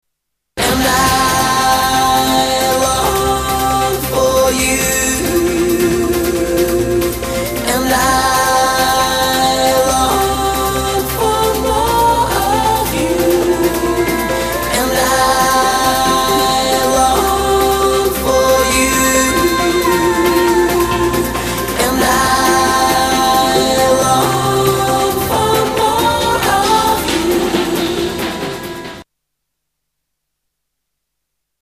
STYLE: Pop
Poppy Eurodance/electronica indie worship.